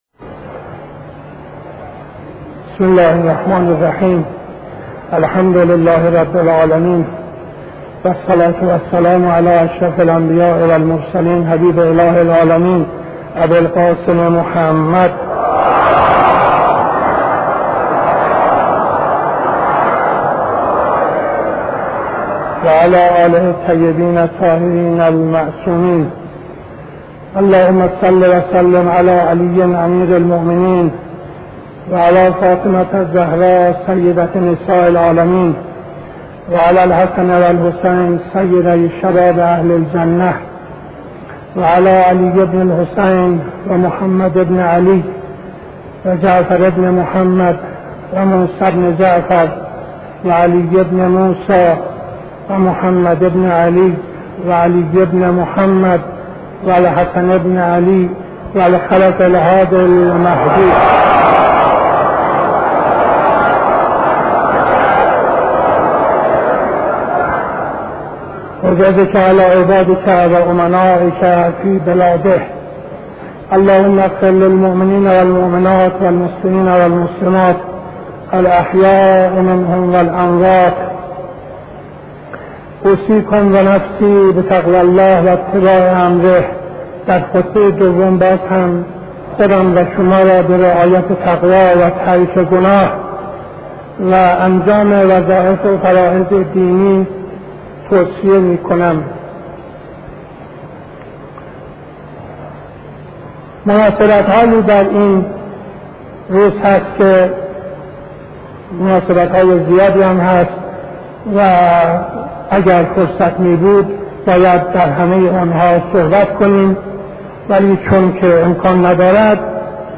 خطبه دوم نماز جمعه 21-03-72